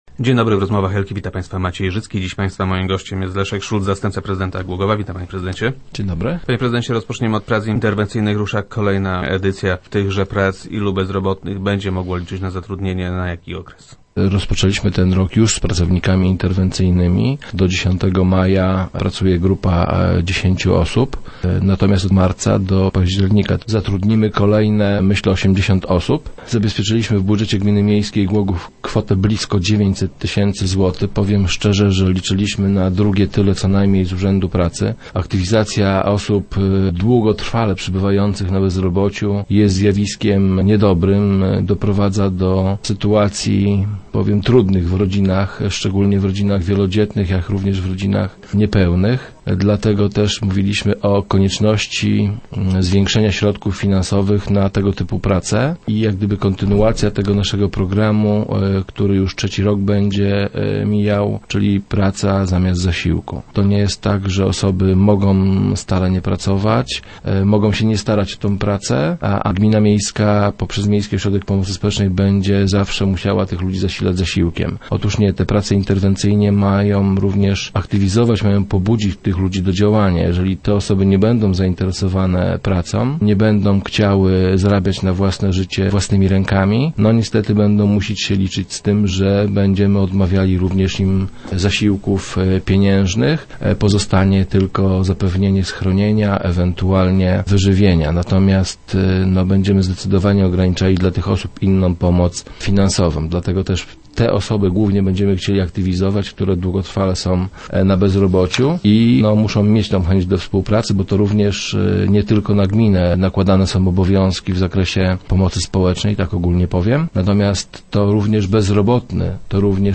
- Będzie to kontynuacja naszego programu „Praca zamiast zasiłku”. Nie może być bowiem tak, że bezrobotni nie starają się znaleźć zatrudnienie, licząc na zasiłki z MOPS-u. Organizowane przez nas prace interwencyjne mają za zadanie zaktywizowanie właśnie takich bezrobotnych. Jeżeli osoby, którym zaproponujemy pracę nie będą nią zainteresowane, będą musiały liczyć się z tym, że będziemy odmawiali im pieniężnych zasiłków i ograniczymy się jedynie do zapewnienia im schronienia i ewentualnie żywności - mówił wiceprezydent Szulc, który był gościem Rozmów Elki.